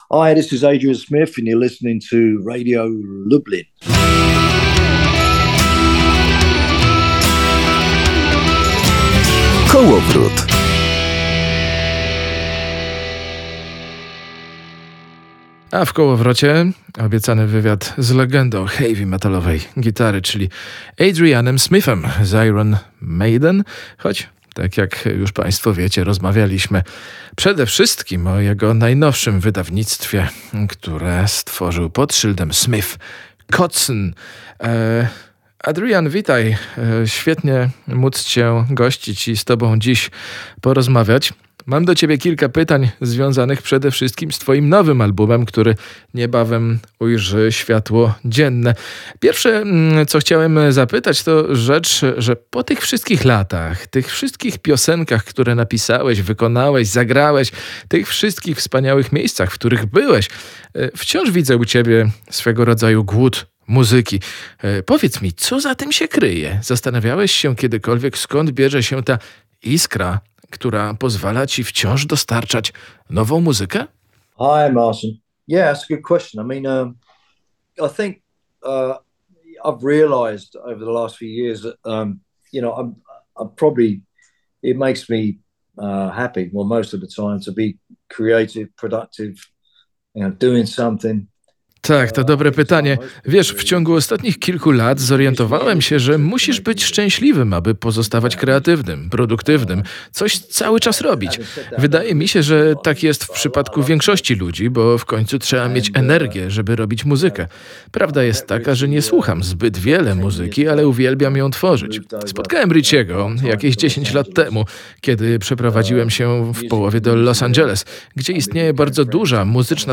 Poniżej znajdziecie zapis naszej rozmowy w wersji audio oraz transkrypcję.